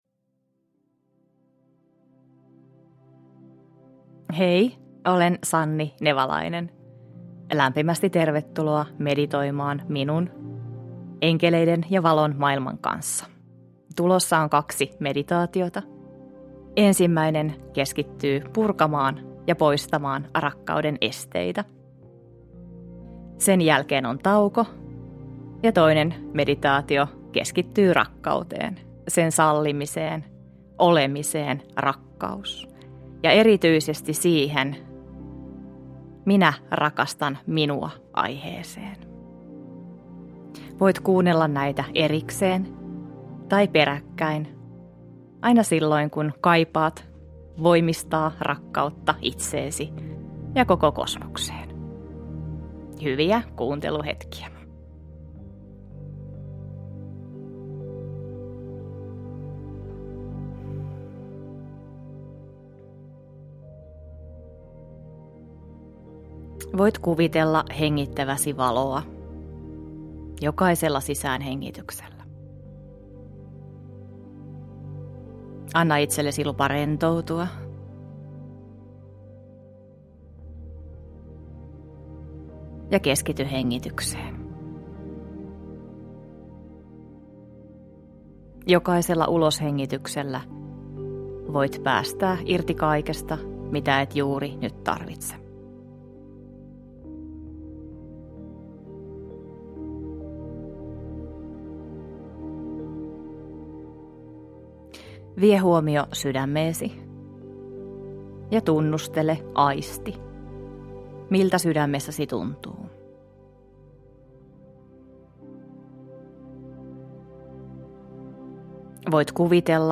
Enkeli meditaatio: Rakkaus – Ljudbok – Laddas ner
Meditaatioäänite sisältää kaksi noin puolen tunnin meditaatiota.